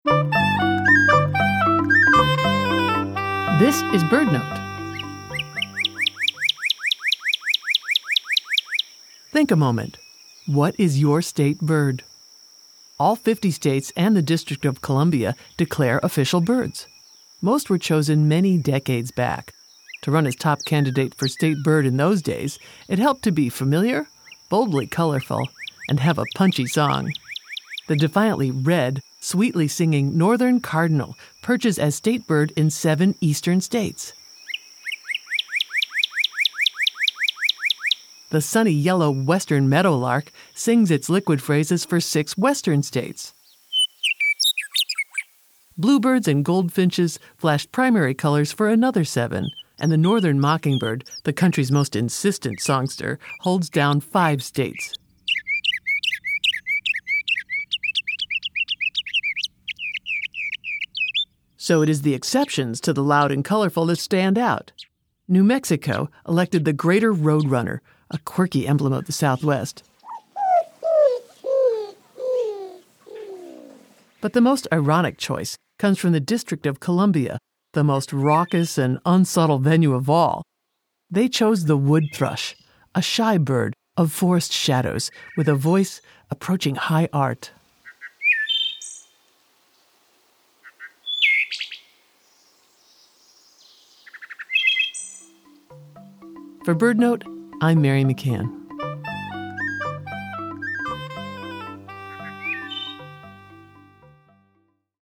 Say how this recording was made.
BirdNote is sponsored locally by Chirp Nature Center and airs live every day at 4 p.m. on KBHR 93.3 FM.